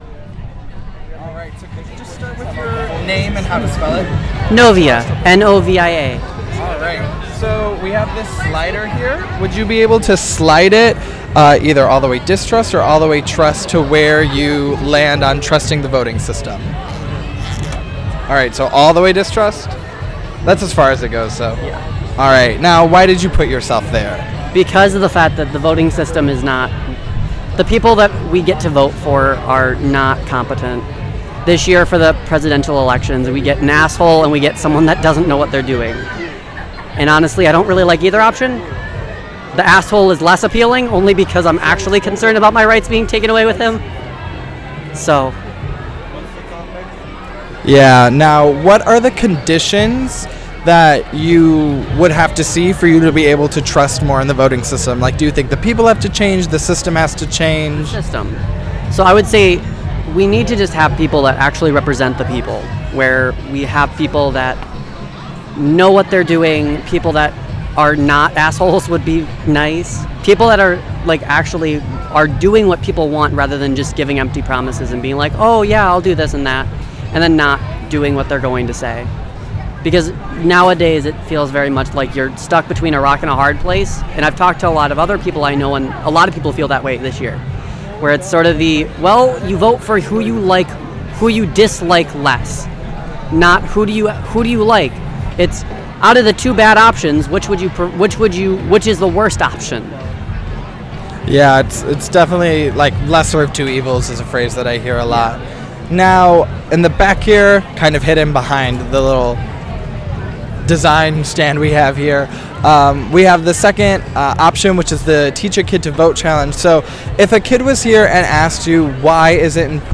Location MKE Pridefest